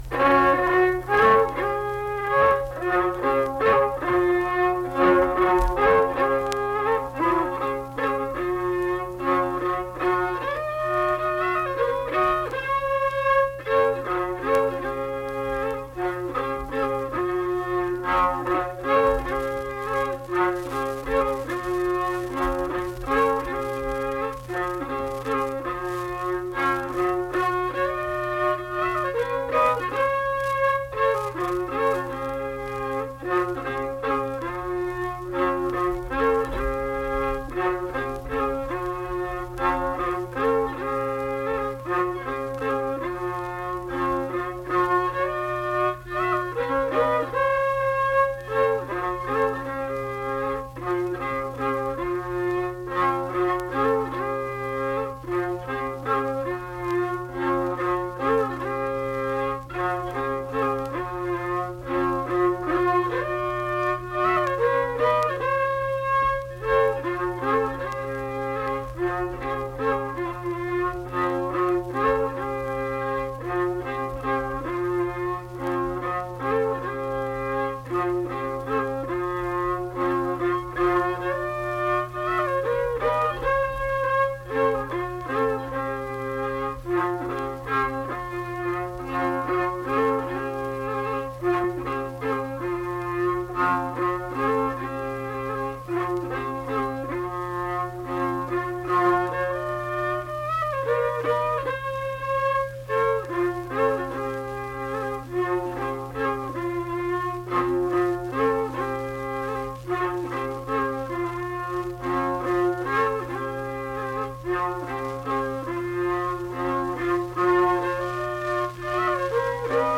Unaccompanied fiddle and vocal music performance
Instrumental Music
Fiddle
Flatwoods (Braxton County, W. Va.), Braxton County (W. Va.)